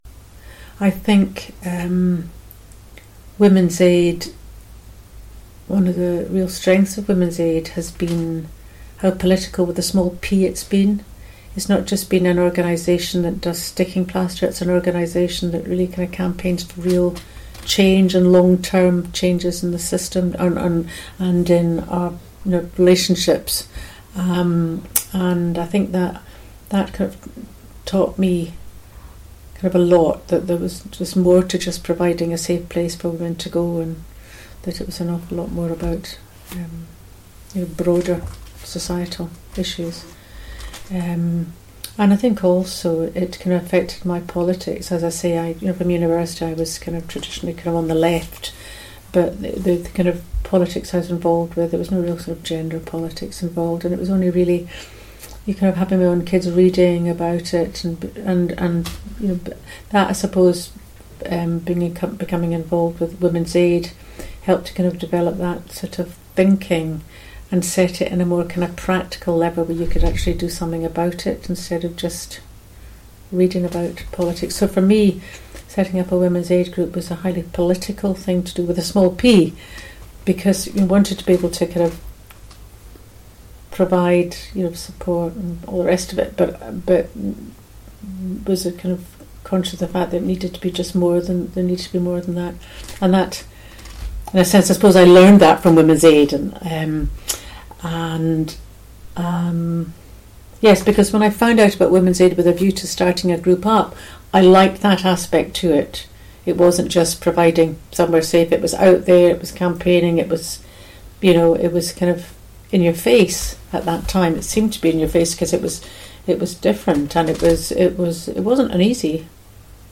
Speaking Out: Oral history clips
The Speaking Out project is gathering oral history interviews with women connected to the Women’s Aid movement, both past and present.